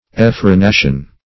Meaning of effrenation. effrenation synonyms, pronunciation, spelling and more from Free Dictionary.
Search Result for " effrenation" : The Collaborative International Dictionary of English v.0.48: Effrenation \Ef`fre*na"tion\, n. [L. effrenatio, fr. effrenare to unbridle; ex + frenum a bridle.]